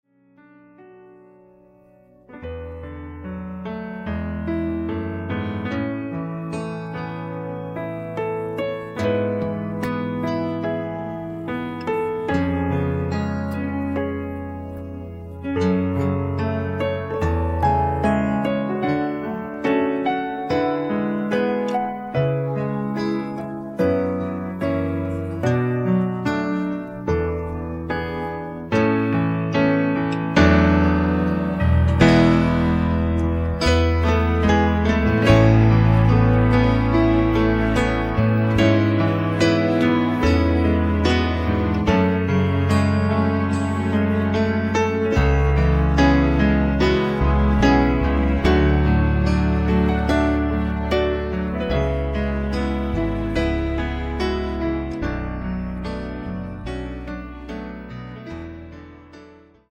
음정 원키 4:26
장르 가요 구분 Voice Cut